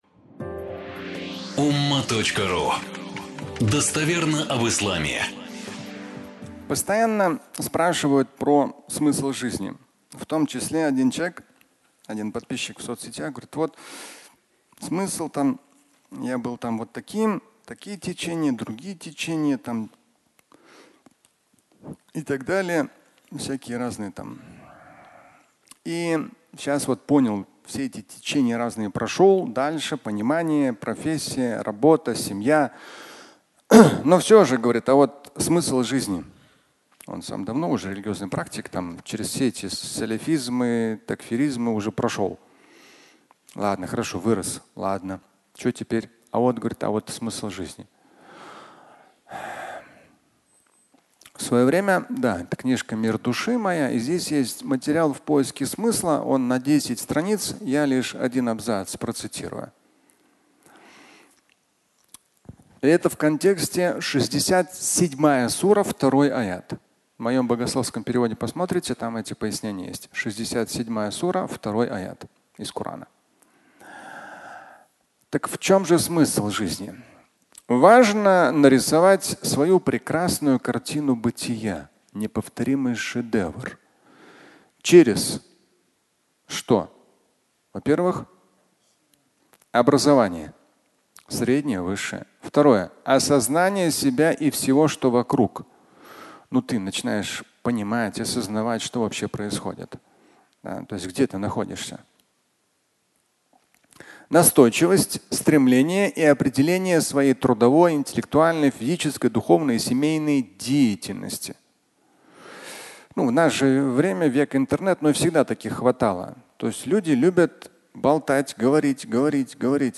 Смысл жизни (аудиолекция)
Пятничная проповедь